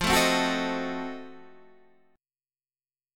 F7b9 chord